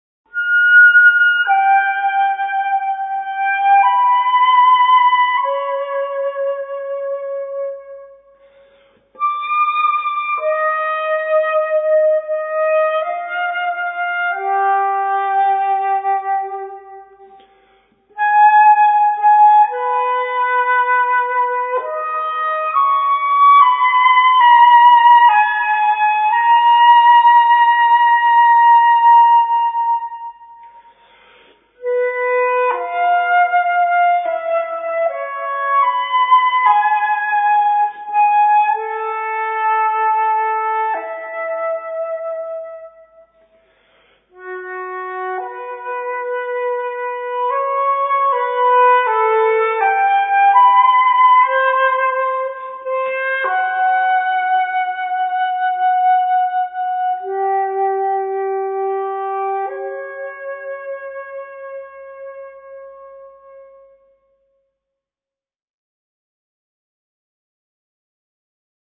Zwölf Rubato-Stückchen für Flöte solo